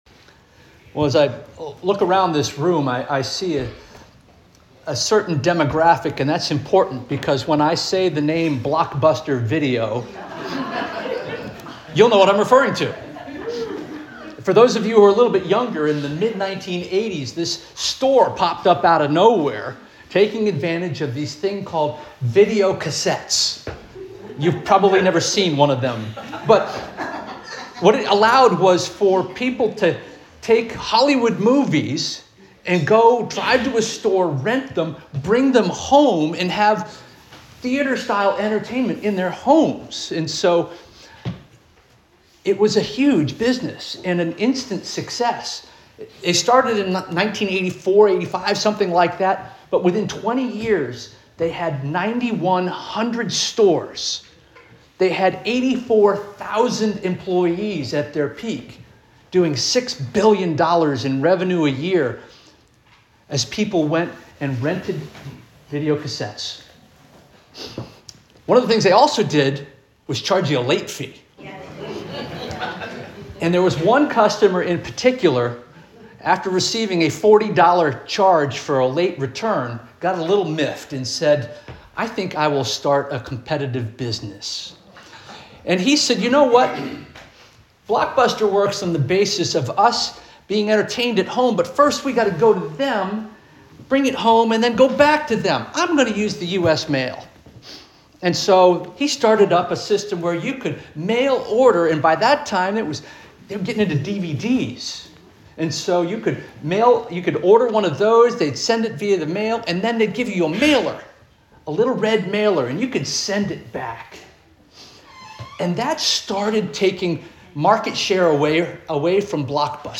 March 22 2026 Sermon - First Union African Baptist Church